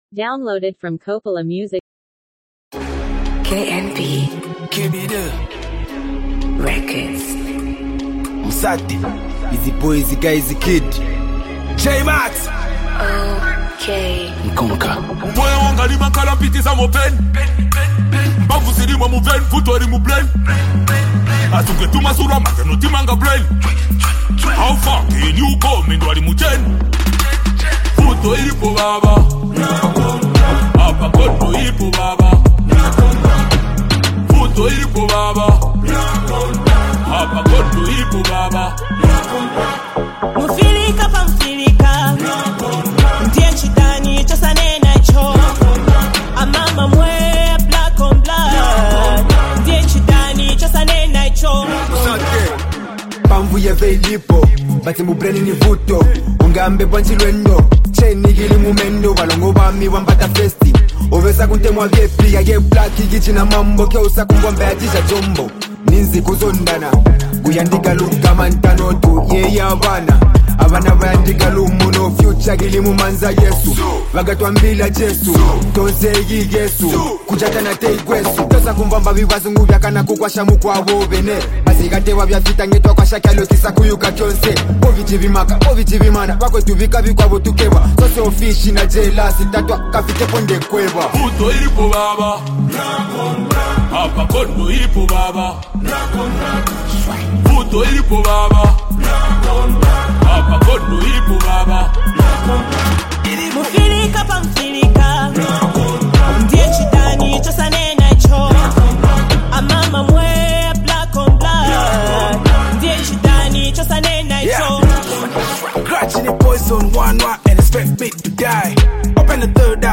bold and energetic hip-hop anthem
catchy and melodic hook that adds rhythm and vibe